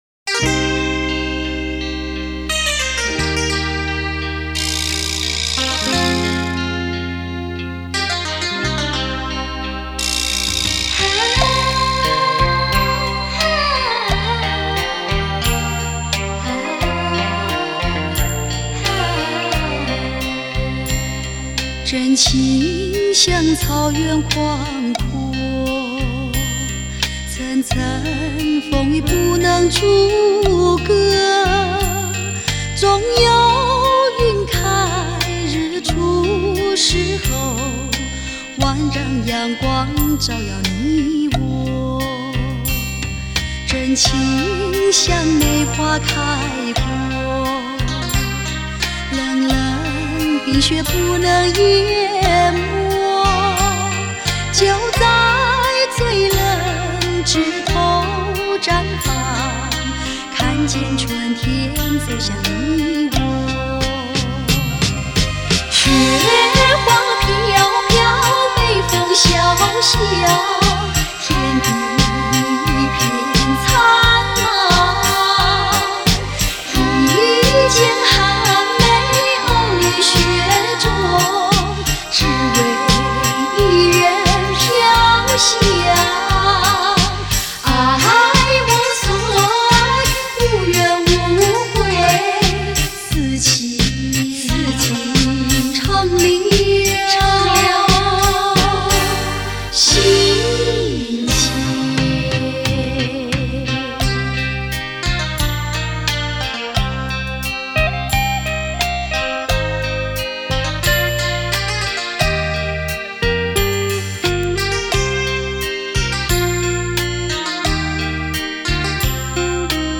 低品质试听